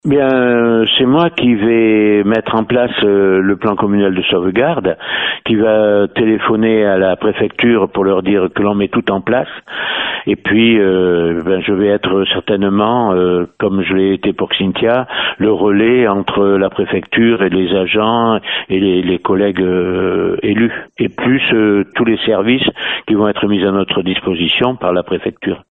Le maire Alain Tuillière nous en dit un peu plus sur le déroulement de cet exercice, même si le scénario est tenu secret par la préfecture :